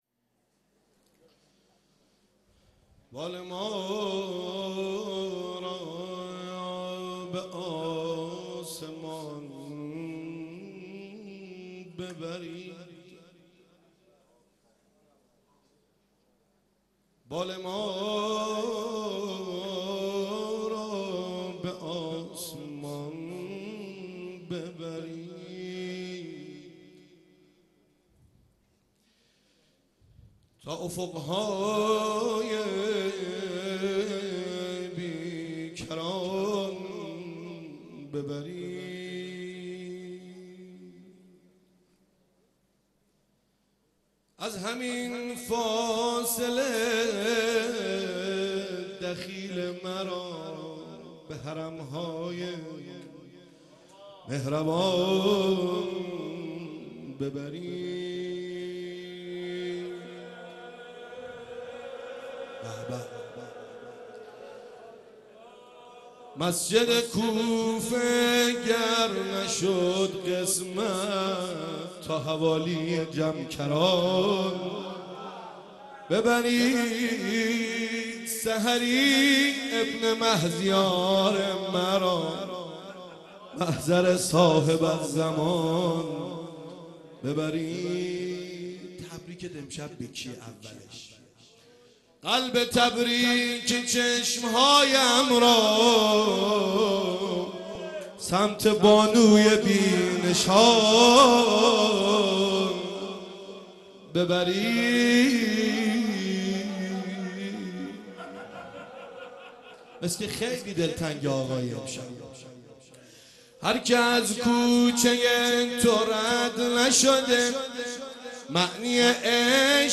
واحد، زمینه